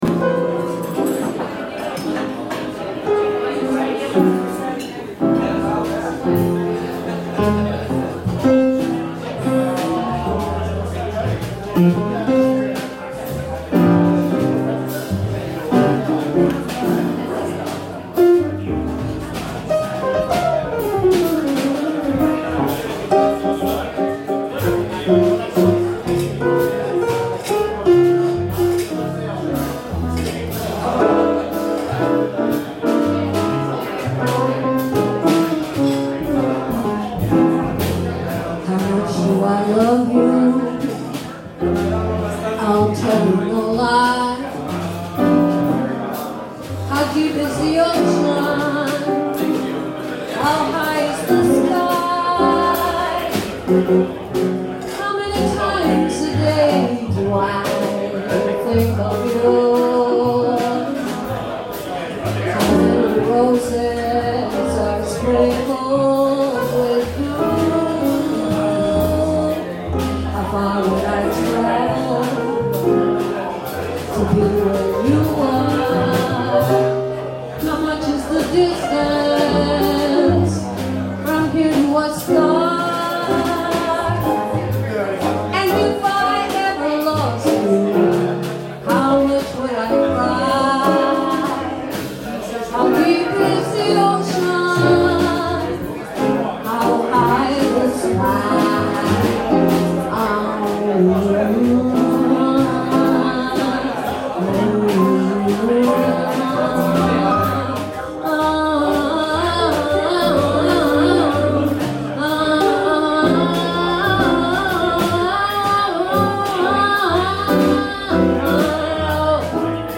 Piano
Bass
Drums
Boston Bebop Jam